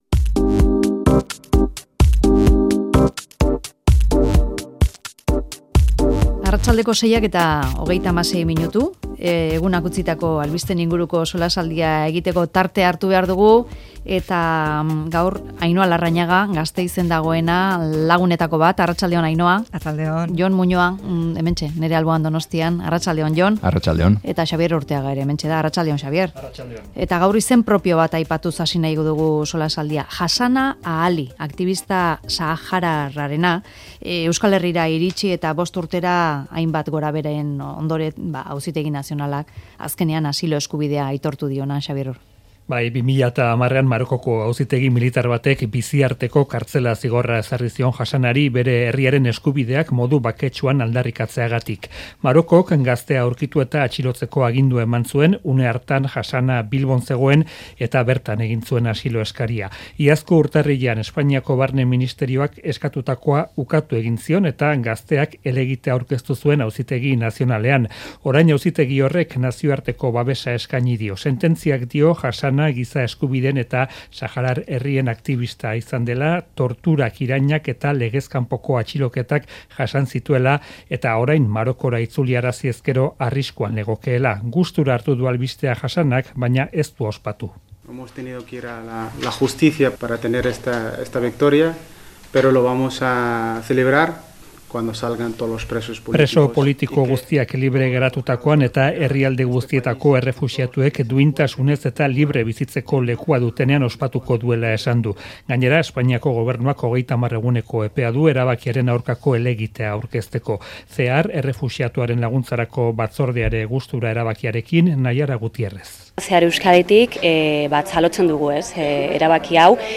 Mezularia|Tertulia